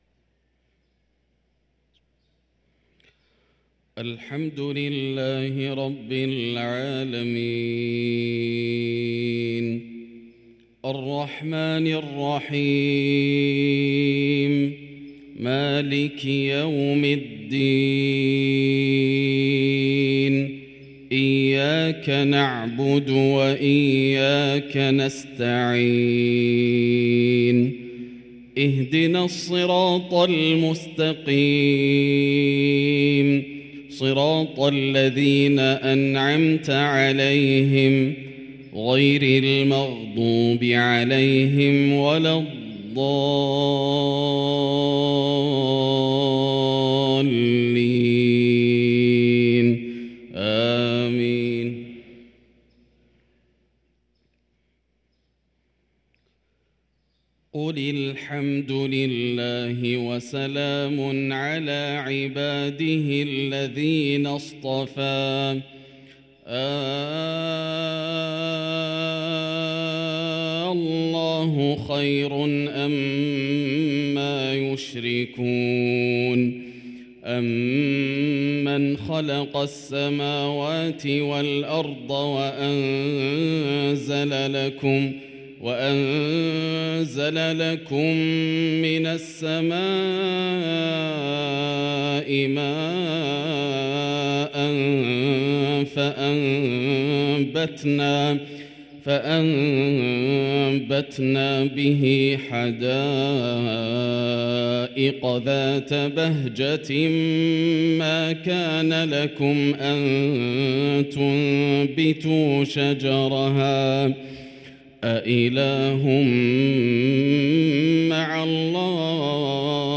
صلاة العشاء للقارئ ياسر الدوسري 11 شعبان 1444 هـ
تِلَاوَات الْحَرَمَيْن .